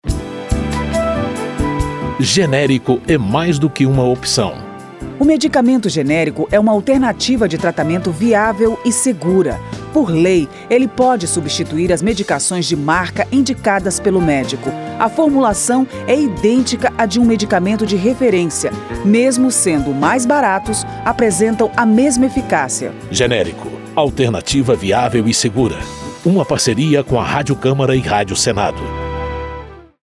spot-02-parceiras-generico-alternativa-segura.mp3